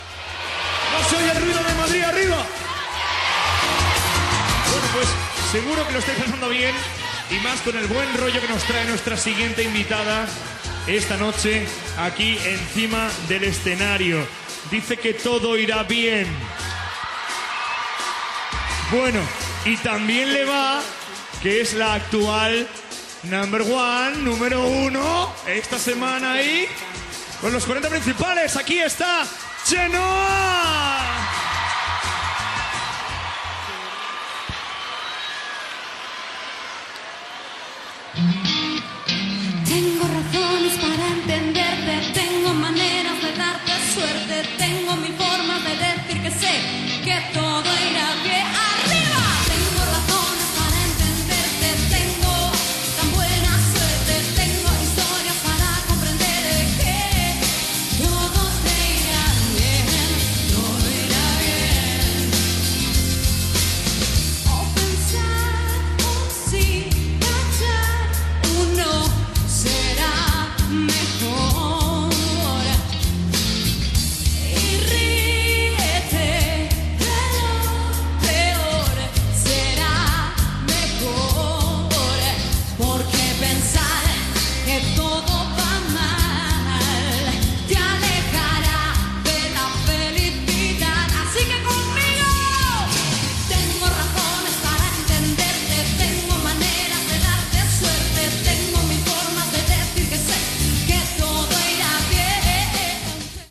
Transmissió des del Palacio de Deportes de la Comunidad de Madrid.
Presentació i actuació de Chenoa (María Laura Corradini ).
Musical